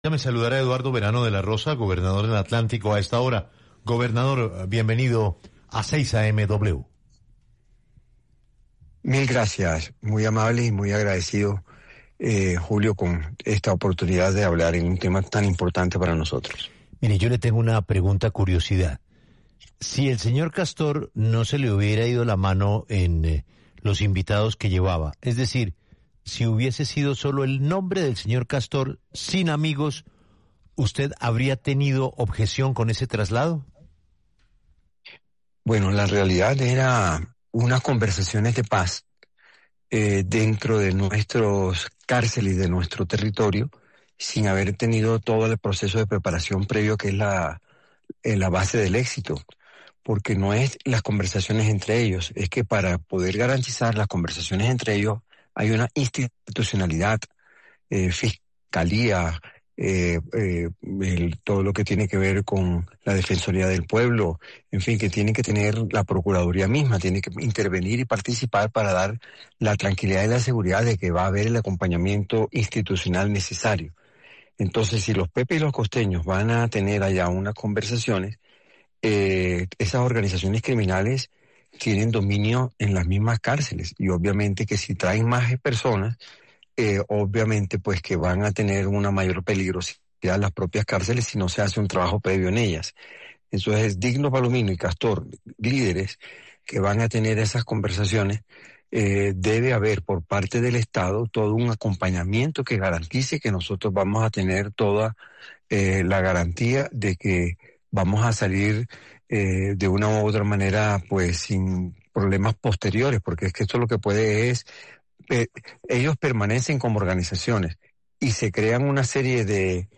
El gobernador del Atlántico, Eduardo Verano de la Rosa, pasó por los micrófonos de 6AM W para hablar sobre la reunión entre ‘Costeños’ y ‘Pepes’, dos de los grupos delincuenciales que hacen presencia en esa región, para definir el futuro de la paz que habían acordado en el 2025 y que vence el 20 de enero de 2026.